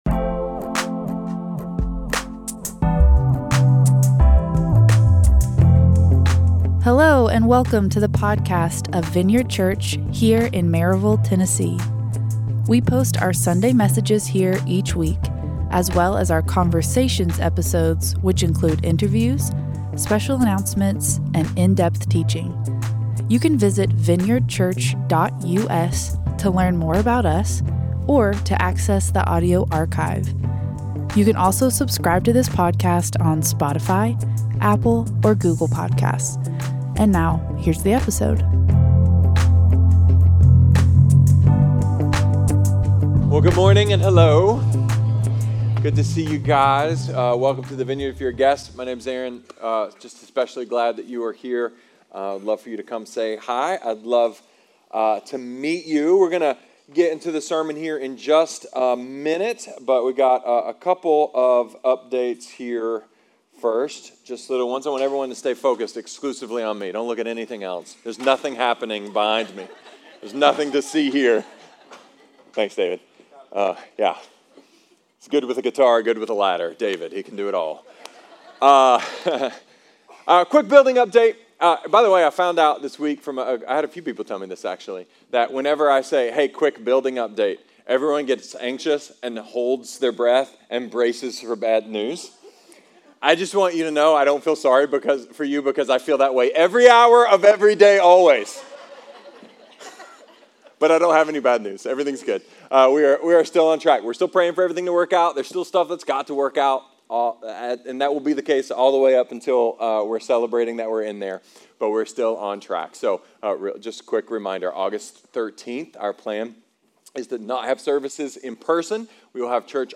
A sermon about liberation, deliberation, slavery disguised as freedom, and the power the real stuff.